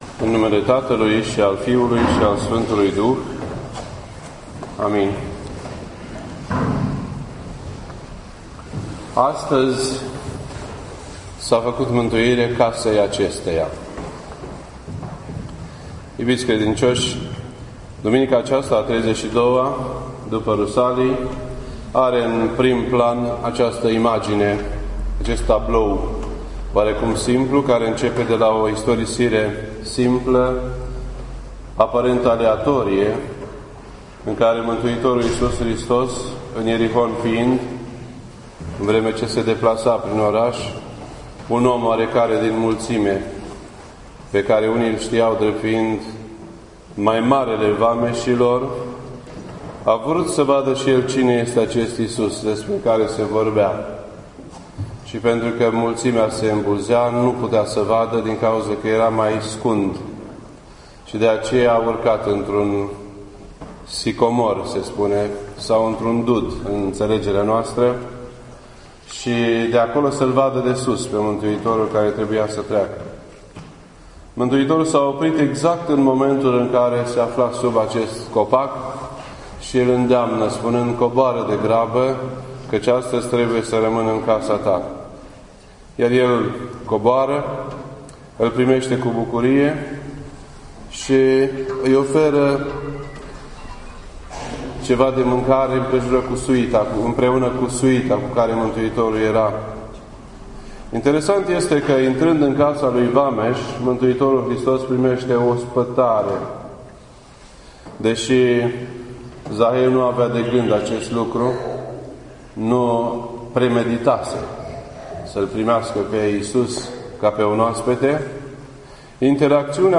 This entry was posted on Sunday, February 3rd, 2013 at 8:42 PM and is filed under Predici ortodoxe in format audio.